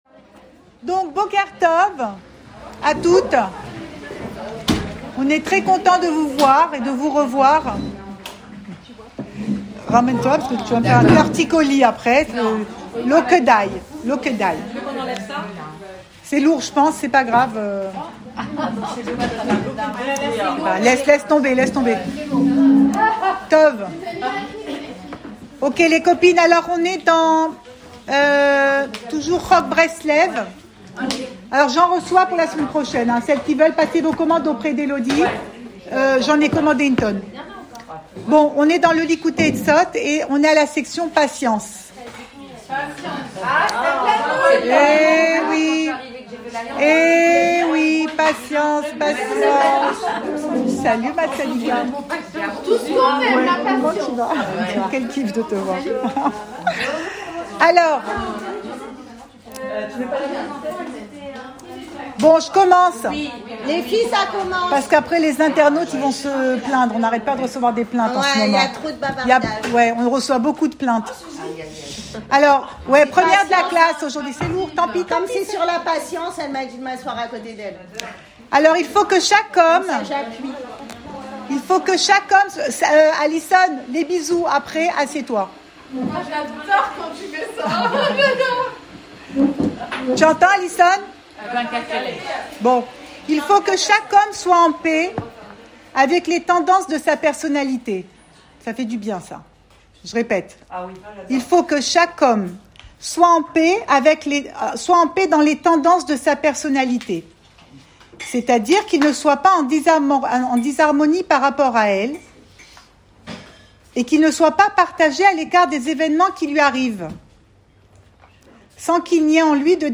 Cours audio Le coin des femmes Pensée Breslev - 10 mai 2023 10 mai 2023 Assieds-toi et ne fais rien. Enregistré à Tel Aviv